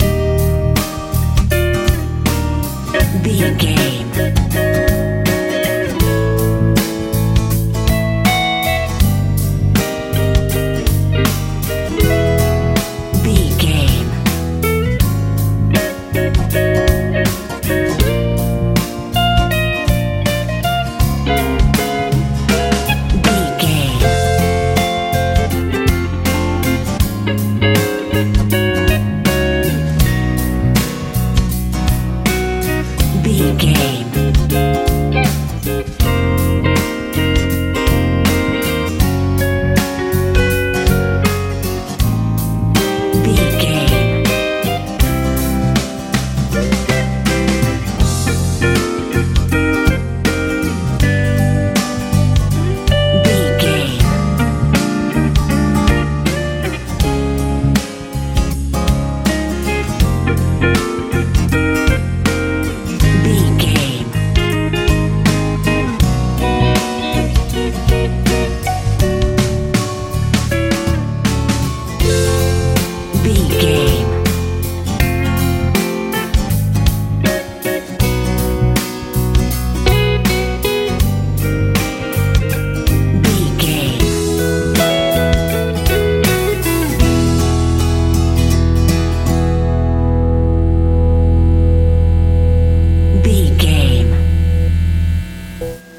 pop rock feel
Ionian/Major
E♭
groovy
bass guitar
drums
electric guitar
acoustic guitar
bright
calm